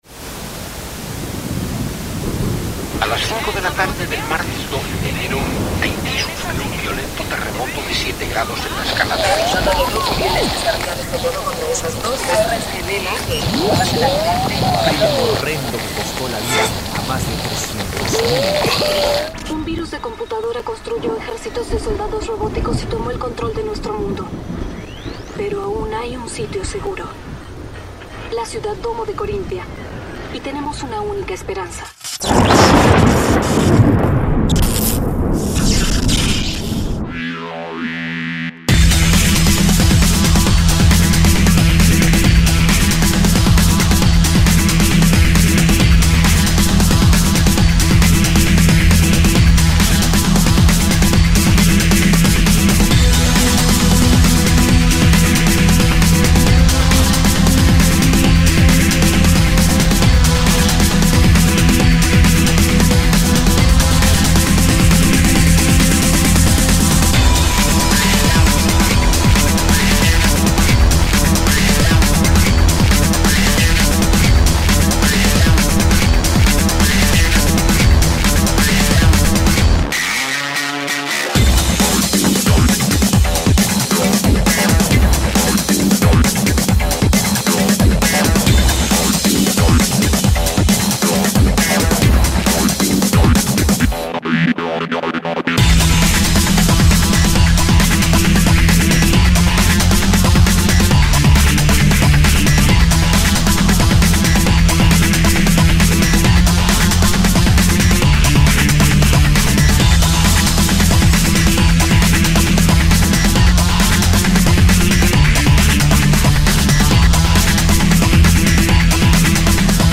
•  Aquí tenemos la sintonía de este año: